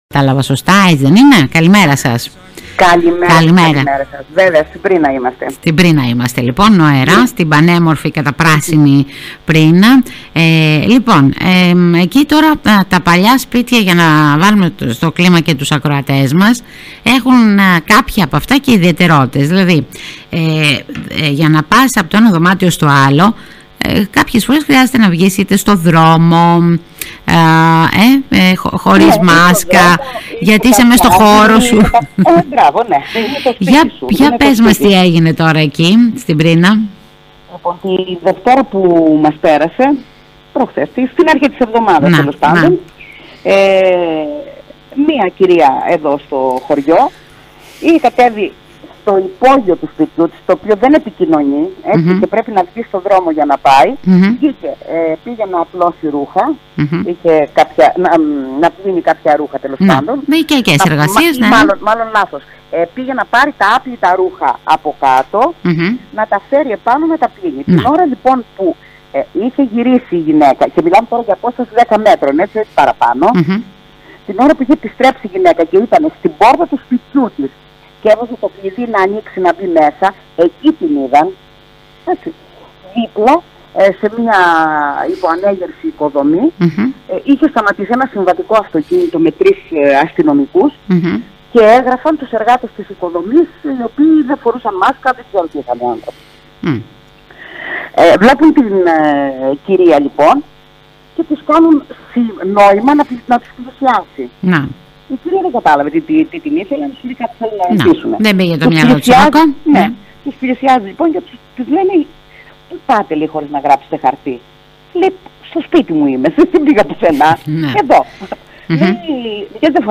Ακροάτρια-Πρίνα-128-kbps.mp3